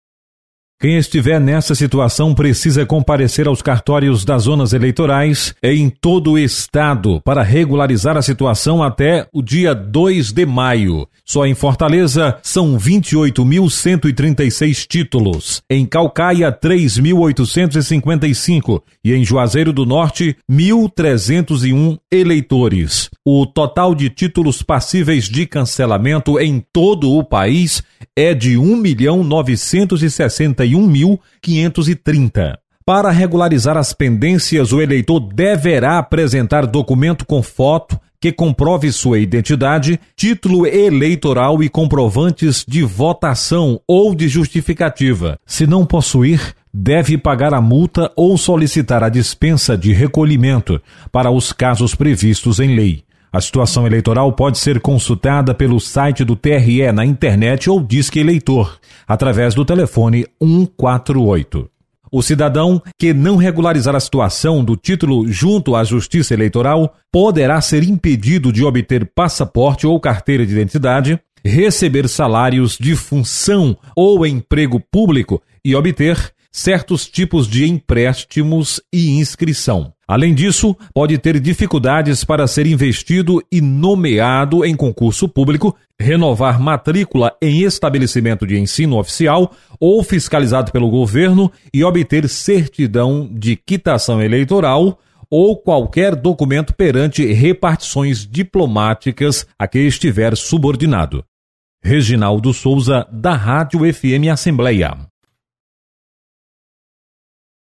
Você está aqui: Início Comunicação Rádio FM Assembleia Notícias TRE